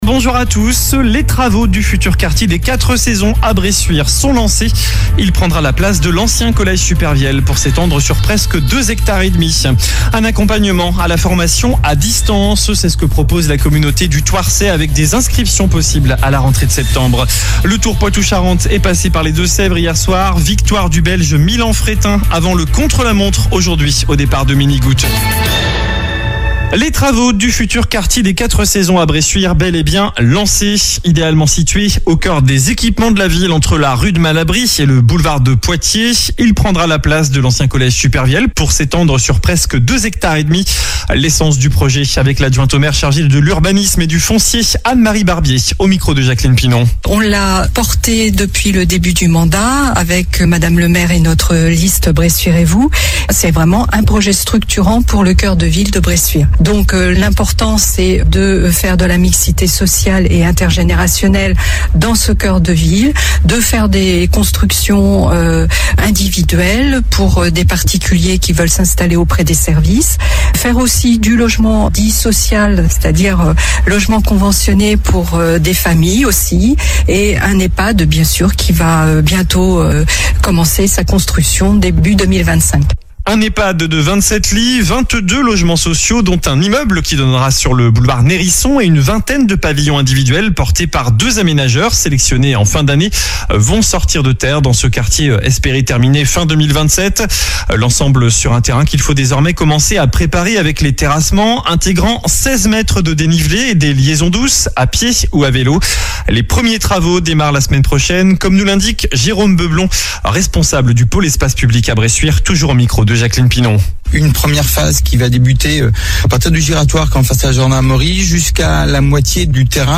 JOURNAL DU JEUDI 22 AOÛT